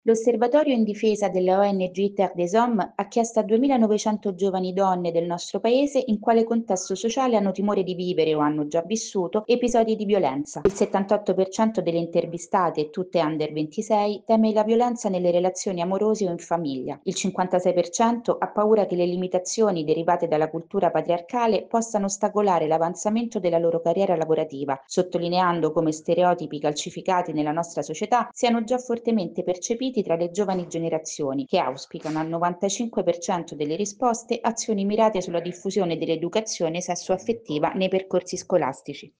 Bisogna agire in questo modo secondo il rapporto Eurispes presentato a Roma. Il servizio